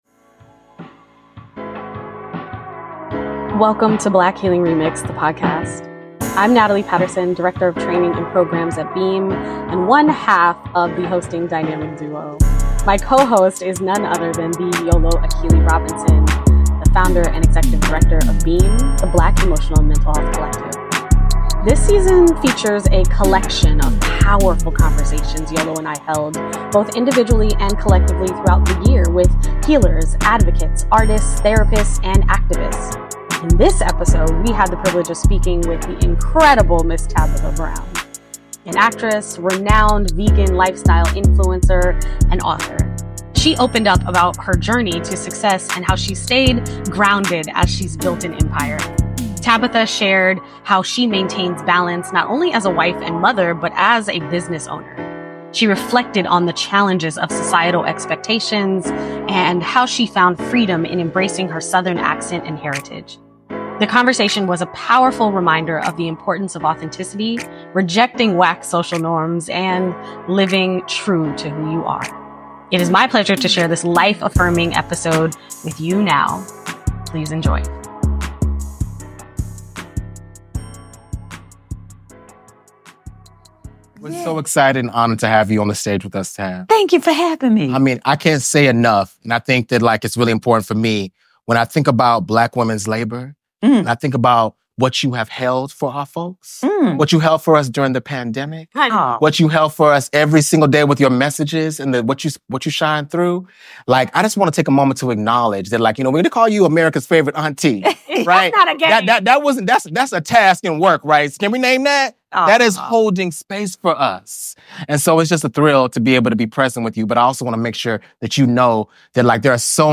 In this episode, we had the privilege of speaking with the incredible Tabitha Brown, a renowned actress, vegan lifestyle influencer, and author, who opened up about her journey to success and how she’s stayed grounded despite her rising fame.